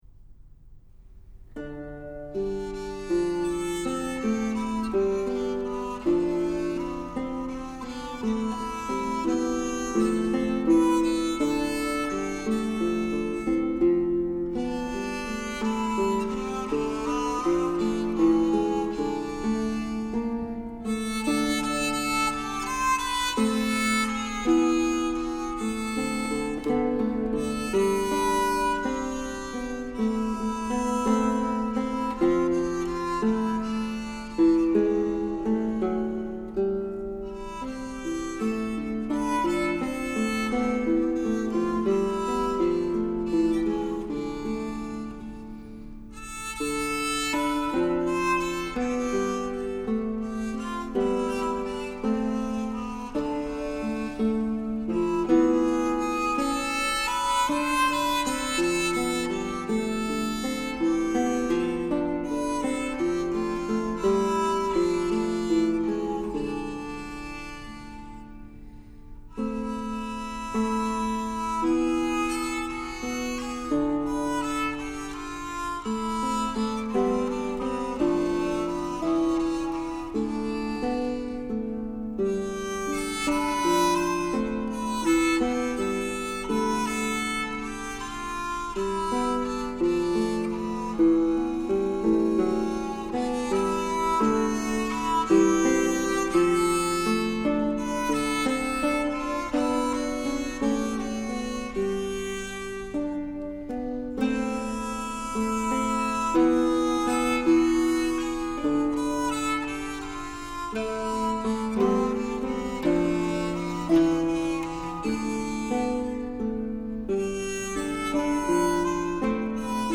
Polyphonies & danses autour de 1400
flutes, cornemuses, organetto
ténor, luth
baryton, tympanon, percussions
harpe
vièle à archet, rebec, bombarde à clef, chalemie
trompette à coulisse
La rugosité (nuancée) des timbres instrumentaux agit comme un révélateur pour les pièces plus connues.